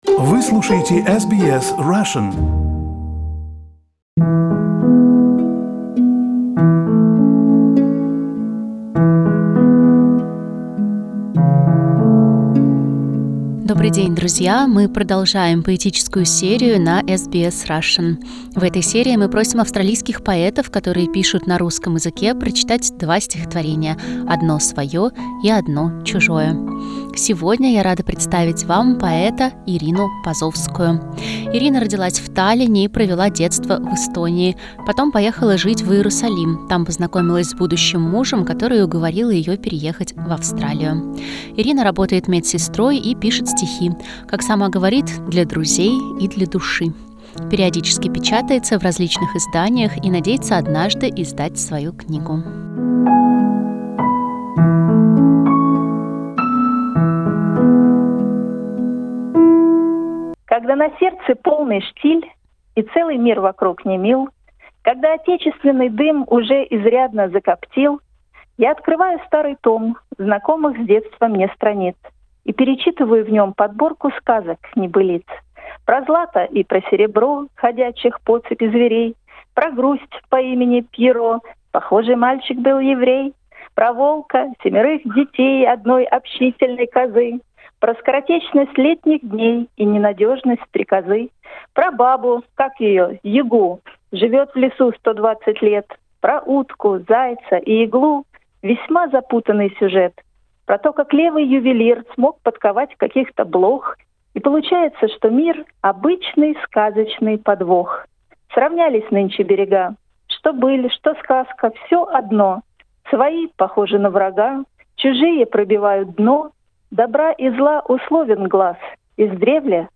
В этой рубрике мы просим австралийских поэтов прочесть два стихотворения: одно свое и одно чужое.